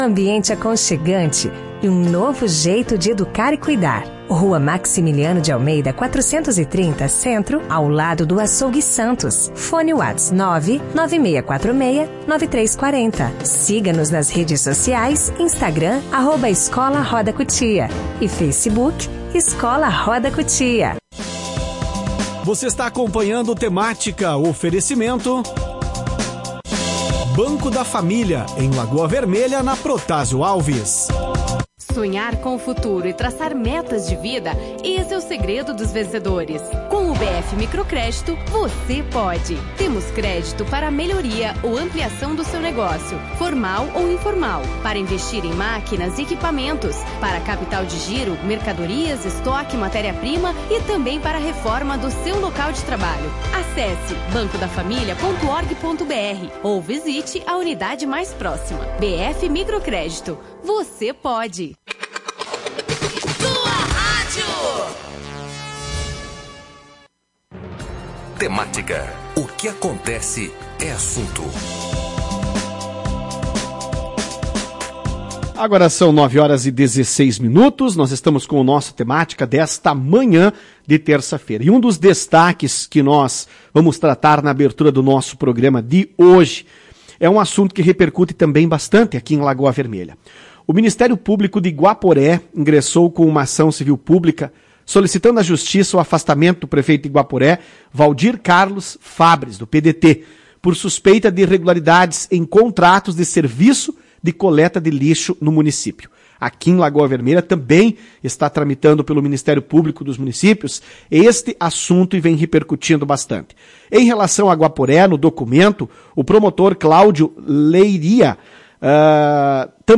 Em entrevista à Tua Rádio, a vereadora falou sobre a investigação